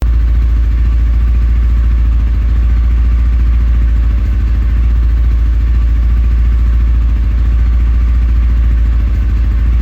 Motorcycle Idle
SFX
yt_8nDD1h54DEI_motorcycle_idle.mp3